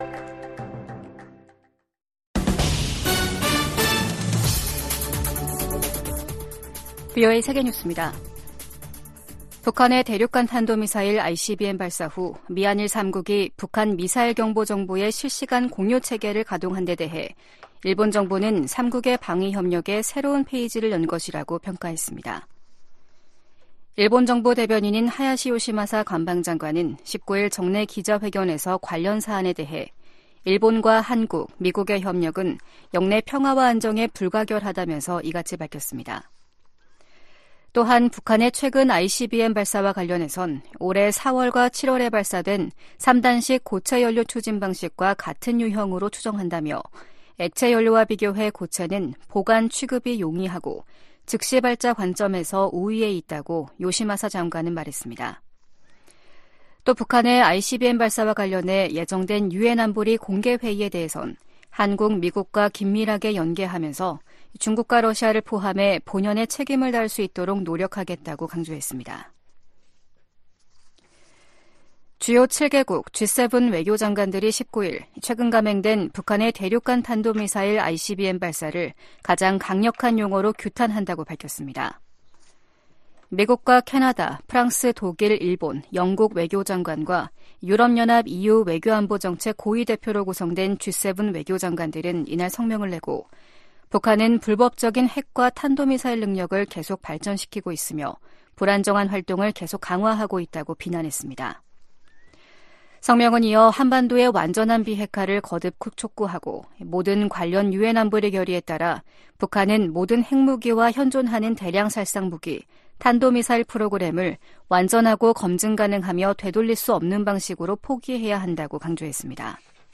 VOA 한국어 아침 뉴스 프로그램 '워싱턴 뉴스 광장' 2023년 12월 20일 방송입니다. 유엔 안보리가 북한의 대륙간탄도미사일(ICBM) 발사에 대응한 긴급 공개회의를 개최합니다. 미 국무부는 중국에 북한의 개발 핵 야욕을 억제하도록 건설적 역할을 촉구했습니다.